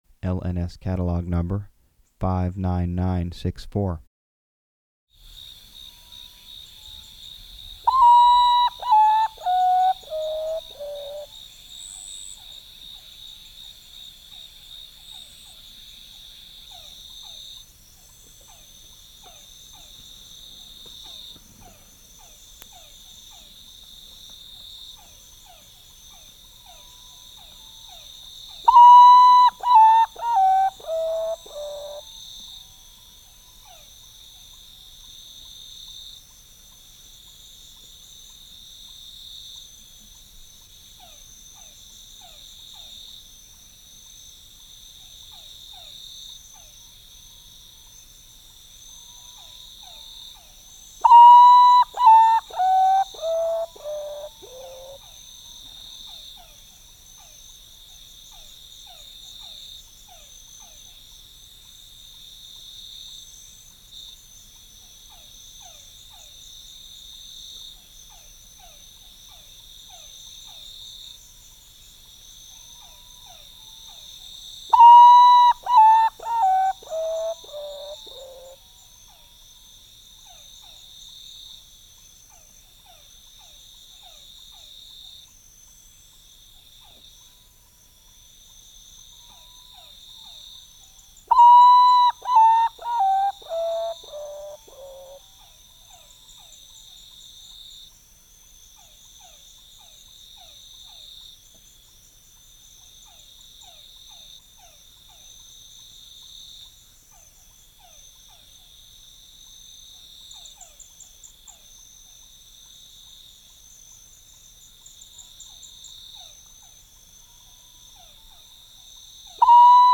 Common Potoo (Nyctibius griseus)
This bird is also known as the "poor-me-one" because it sings a plaintive descending whistle that has been phoneticized as "poor me, all alone" audio .